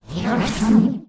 Archer Gibberishes
弓箭手胡言乱语